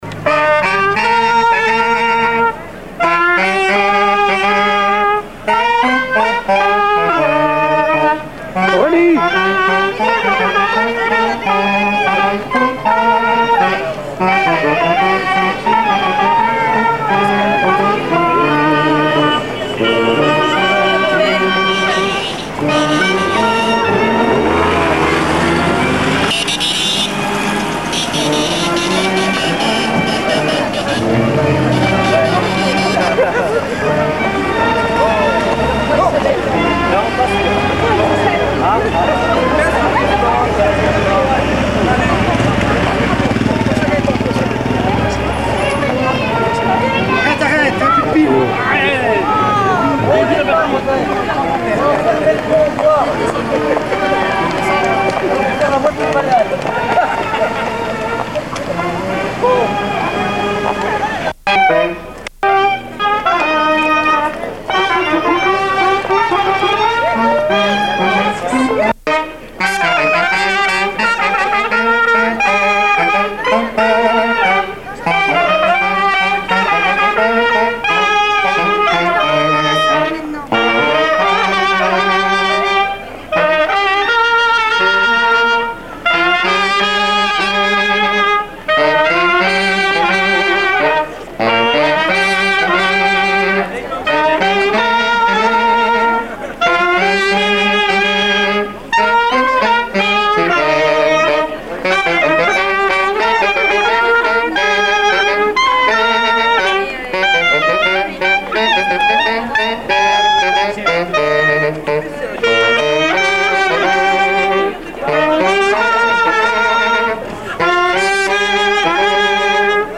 Saint-Hilaire-de-Talmont
Marches de cortège de noce
Pièce musicale inédite